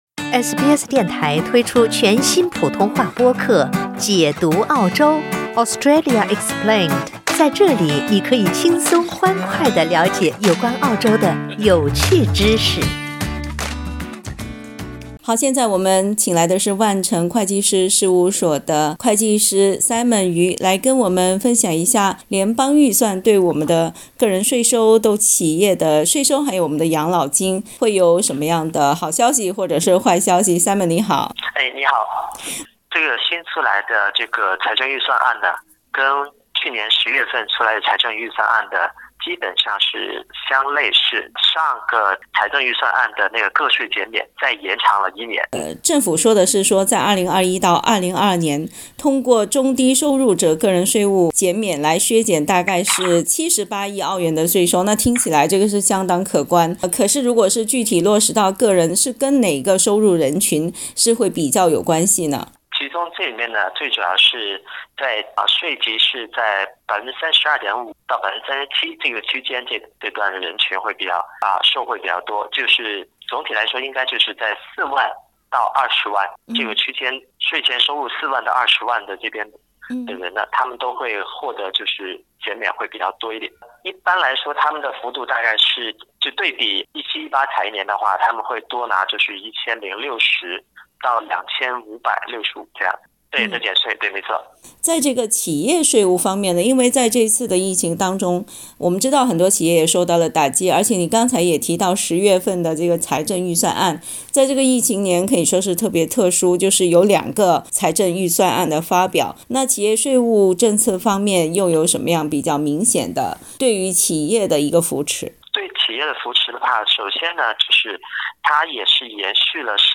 （请听采访） 澳大利亚人必须与他人保持至少1.5米的社交距离，请查看您所在州或领地的最新社交限制措施。